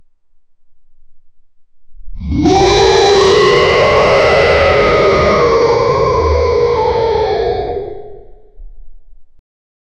Anger Sound Effects - Free AI Generator & Downloads
hulk-angry-roar-zu45ujf6.wav